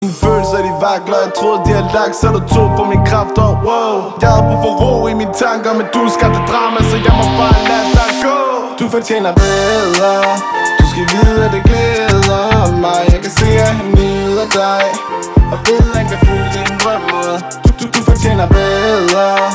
• Hip hop
hiphop/rap koncert med originale sange og fængende melodier